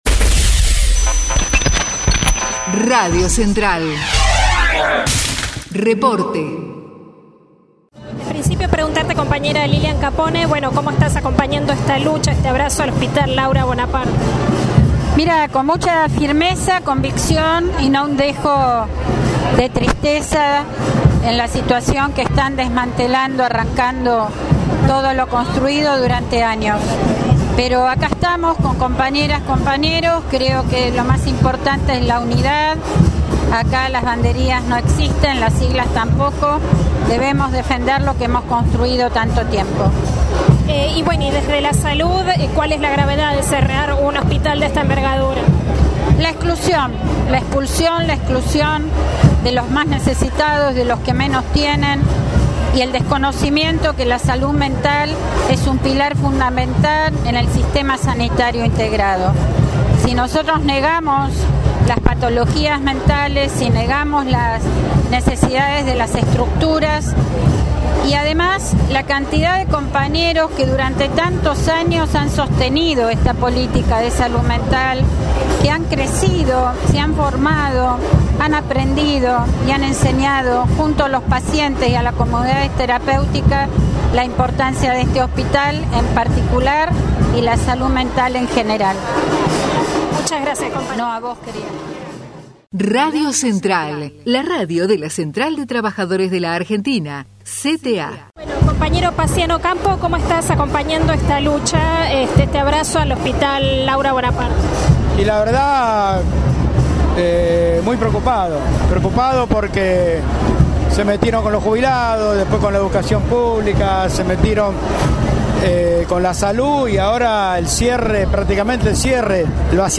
Frente a las escalinatas del Hospital Bonaparte